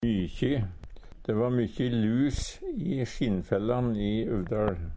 DIALEKTORD PÅ NORMERT NORSK mykje mykje Ubunde han-/hokj.